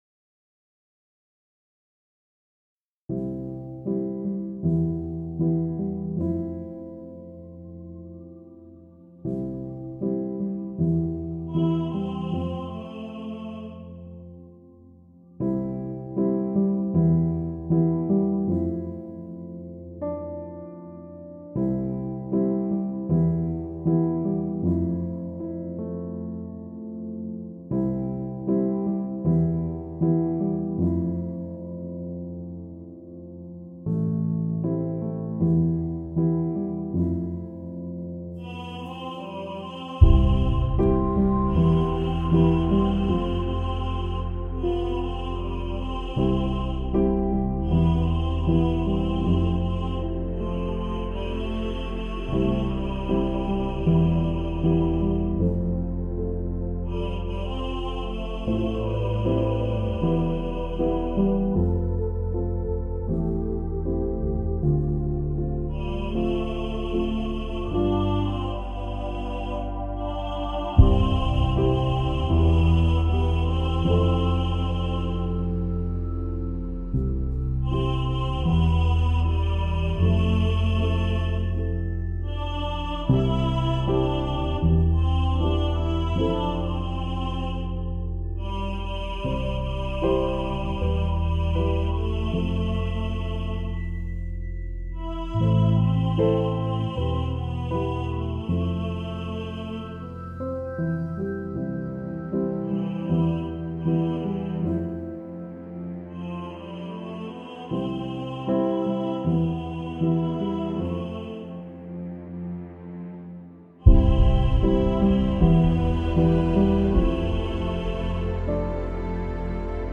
What Was I Made For – Tenor | Ipswich Hospital Community Choir
What-Was-I-Made-For-Tenor.mp3